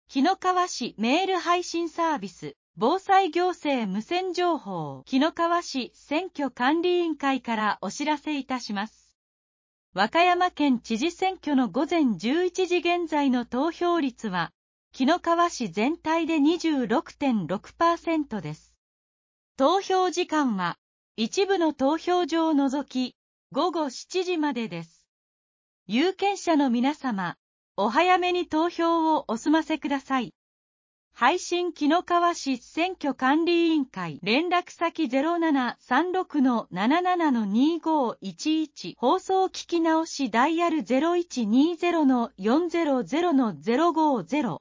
紀の川市メール配信サービス 【防災行政無線情報】